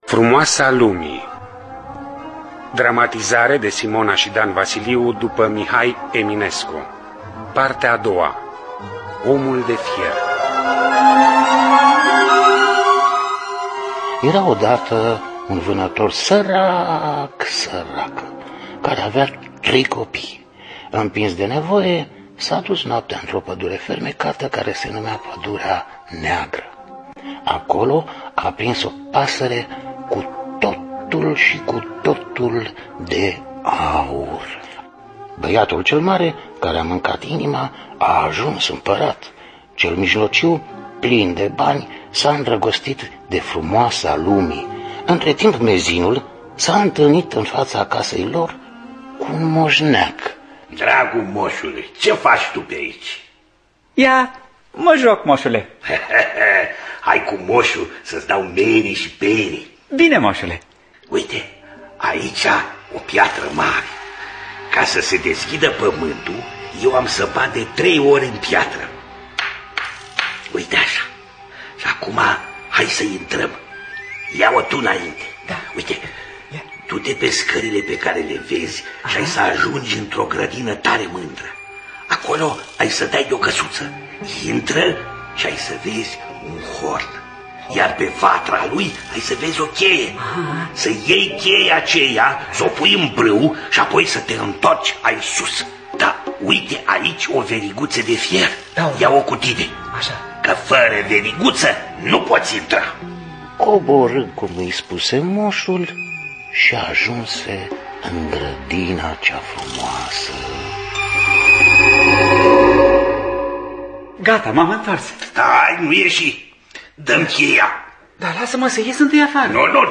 Dramatizarea radiofonică de Simona și Dan Vasiliu.